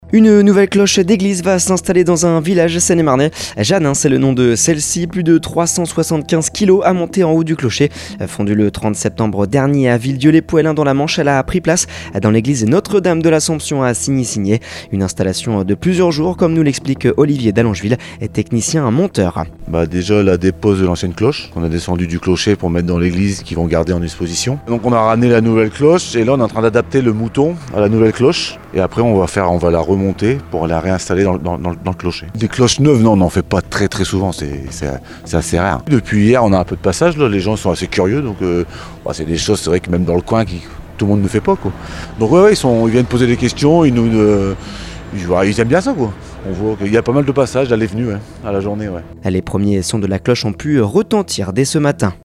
Les premiers sons de la cloche ont pu retentir !